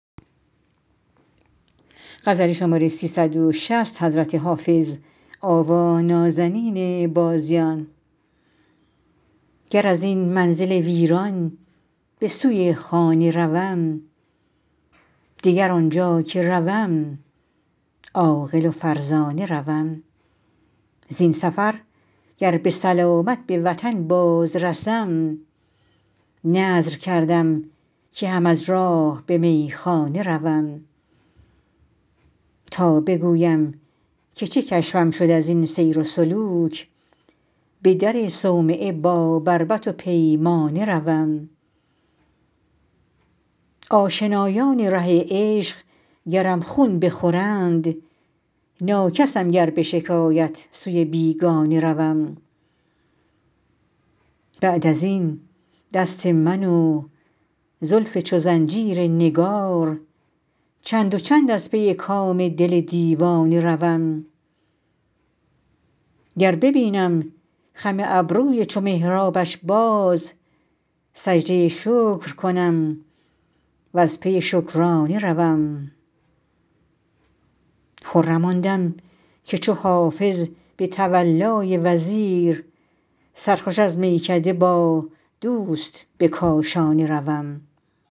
حافظ غزلیات غزل شمارهٔ ۳۶۰ به خوانش